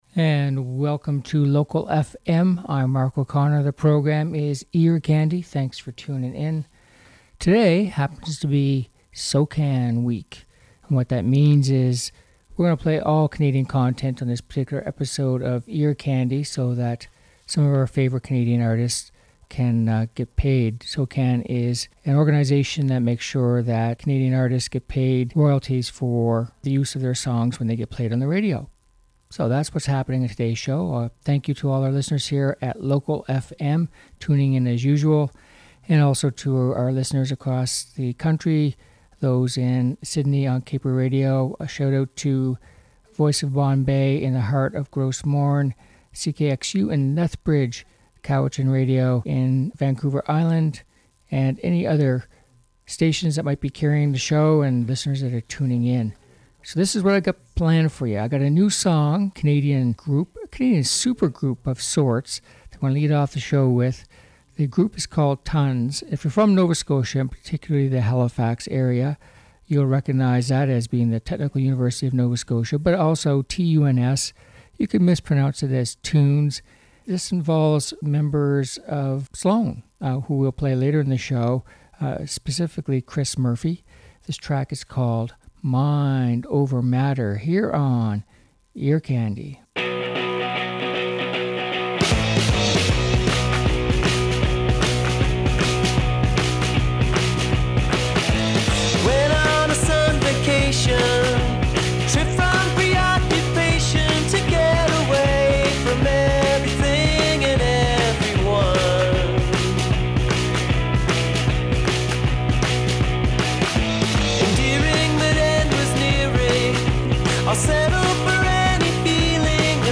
All Cancon Episode for SOCAN - Upbeat Catchy Pop and Rock Songs